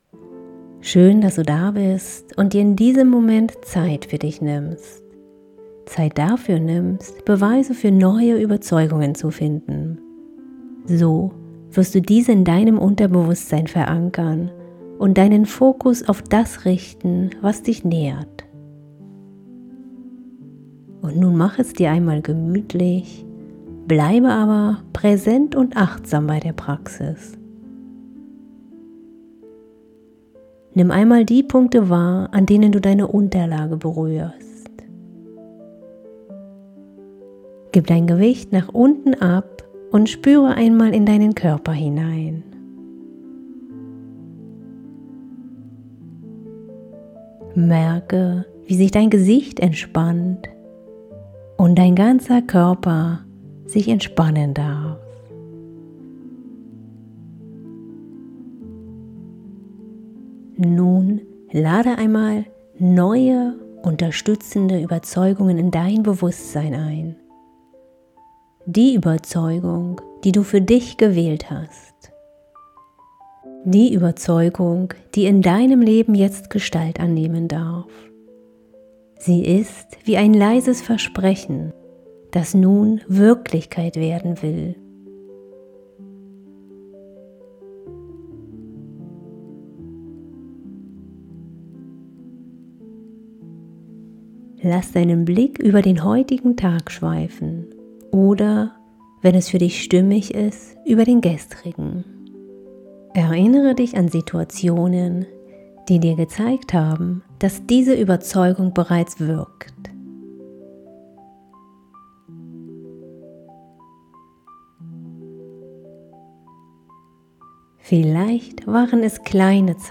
Starte jetzt deine Meditation. Vertiefe das, was du im Video erfahren hast – und sammle neue Beweise für deine Stärke. lade dir hier die Meditation herunter